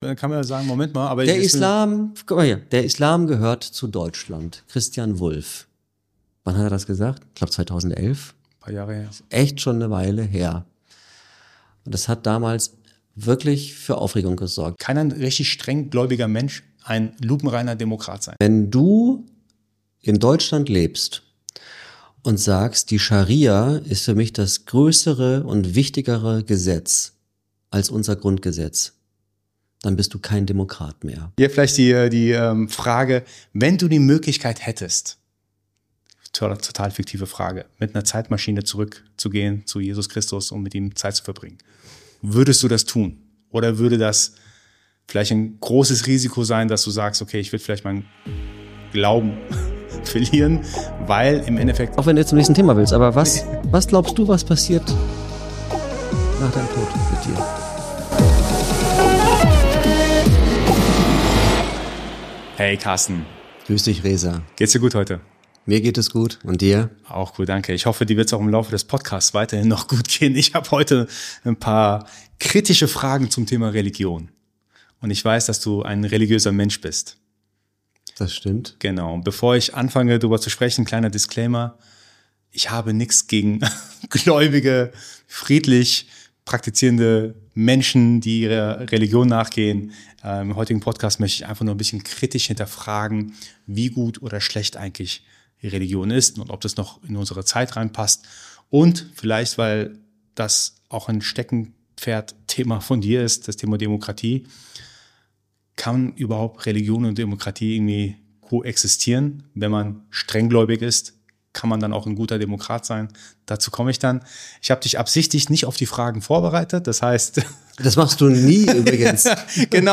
Ein Gespräch über Glauben, Zweifel, Werte, Demokratie, Radikalisierung, Scharia, Kirche, Islam in Deutschland und den Umgang mit Religion in unserer Zeit.